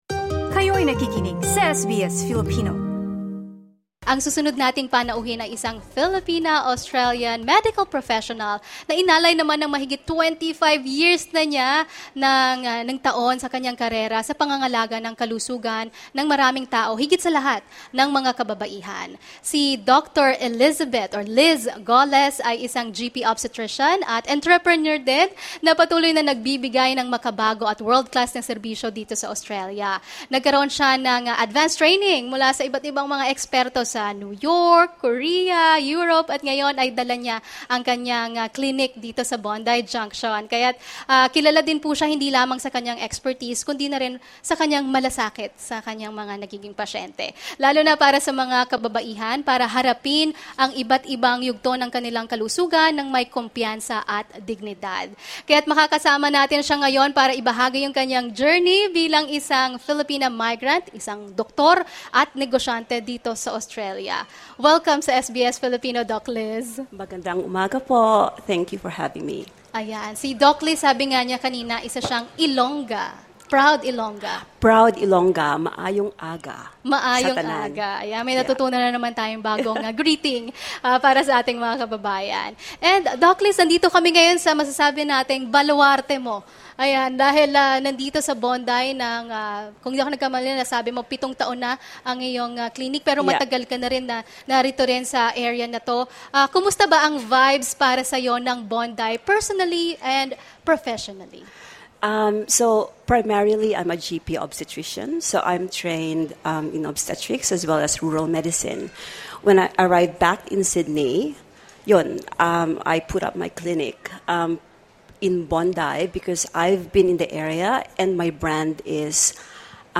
‘Everyone Belongs’: SBS marks Harmony Day with special multi-lingual broadcast at Bondi